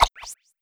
rightThrowNoShake.ogg